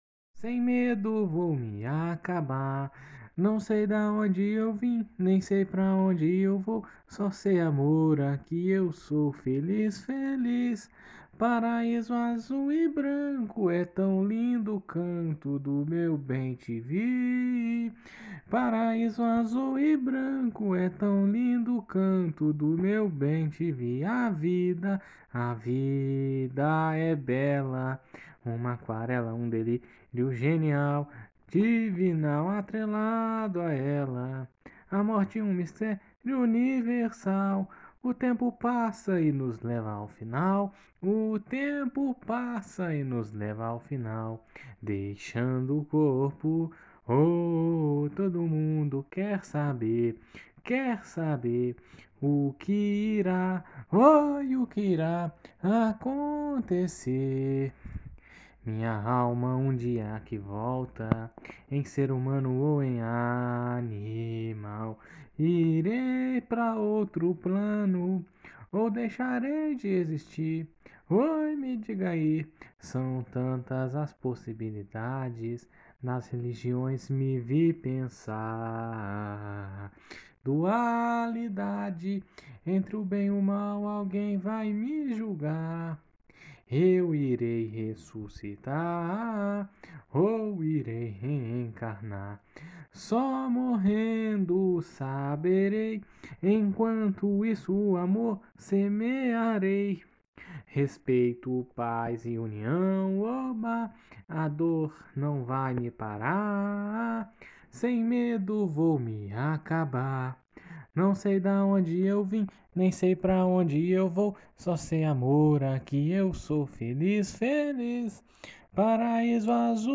Samba  02